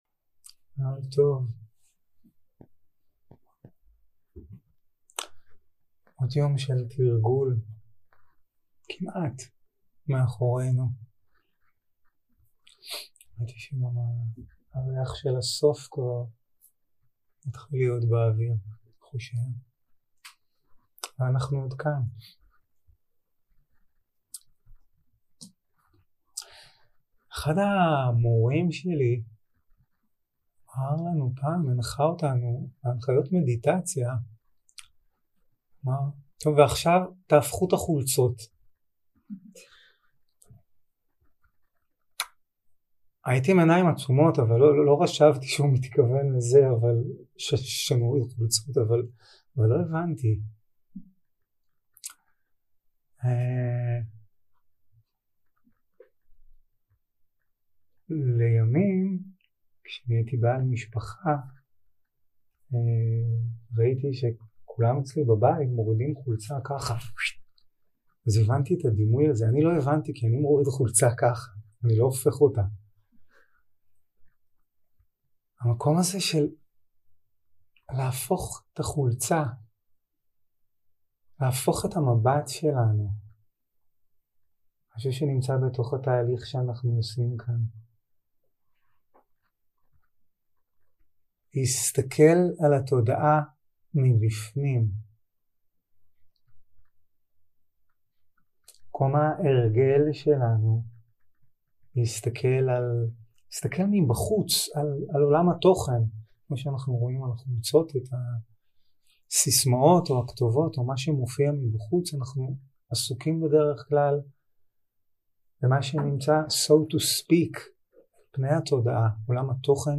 יום 4 - הקלטה 8 - ערב - שיחת דהרמה - The ultimate unfindability of self 0:00 0:00 סוג ההקלטה: Dharma type: Dharma Talks שפת ההקלטה: Dharma talk language: Hebrew